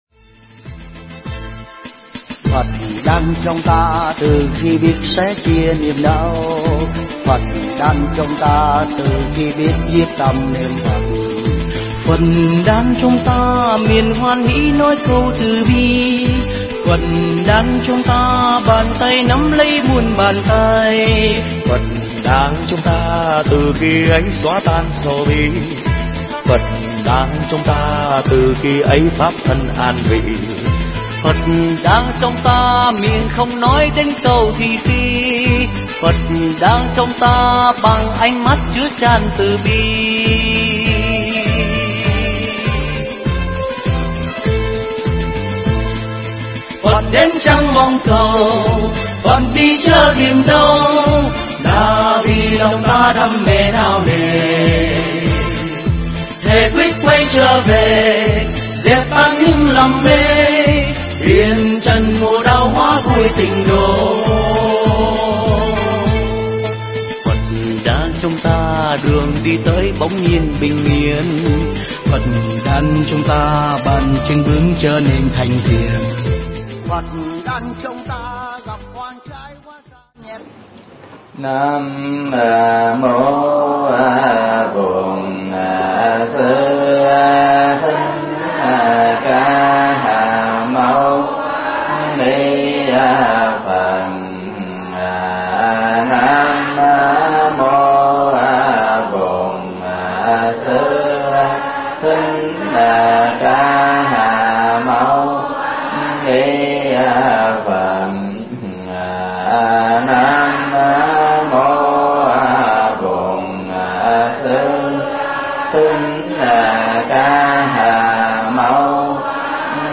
Mp3 Thuyết Pháp Thờ Phật